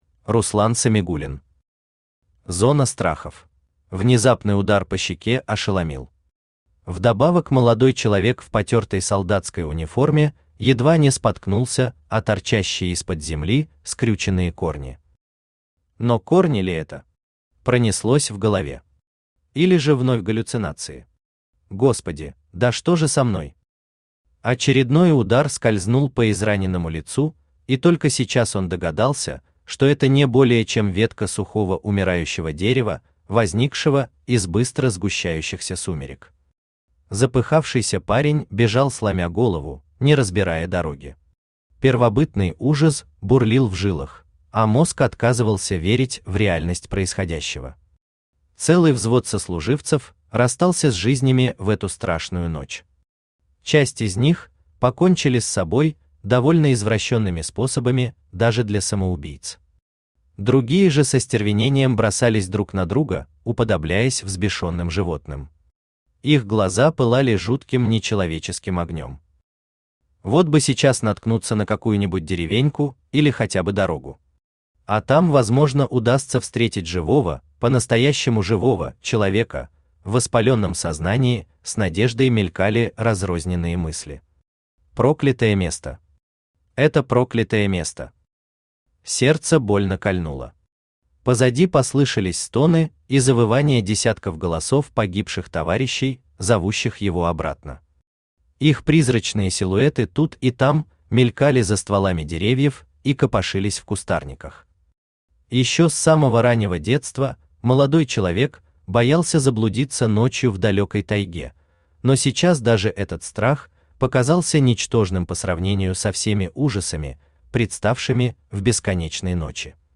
Аудиокнига Зона страхов | Библиотека аудиокниг
Aудиокнига Зона страхов Автор Руслан Альфридович Самигуллин Читает аудиокнигу Авточтец ЛитРес.